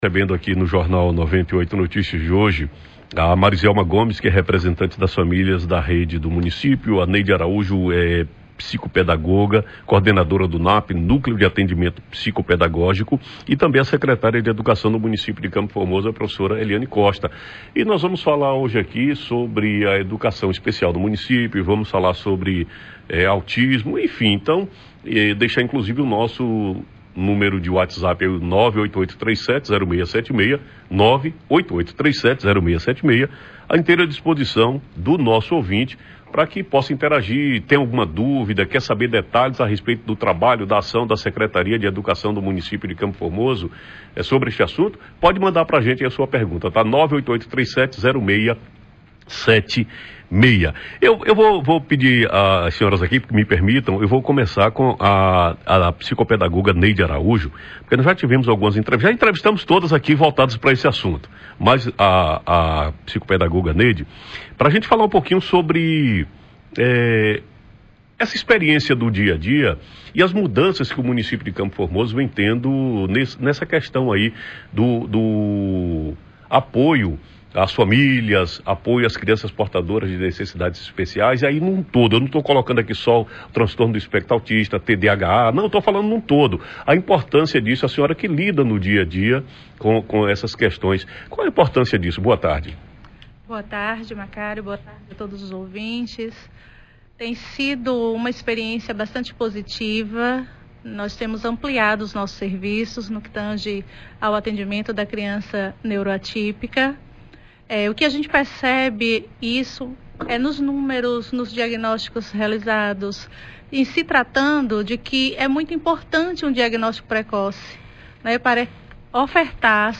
Representantes da especial do município de Campo Formoso falam das programações do Abril Azul
entrvista.mp3